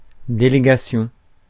Ääntäminen
Ääntäminen France: IPA: [de.le.ga.sjɔ̃] Tuntematon aksentti: IPA: /de.le.ɡa.sjɔ̃/ Haettu sana löytyi näillä lähdekielillä: ranska Käännöksiä ei löytynyt valitulle kohdekielelle.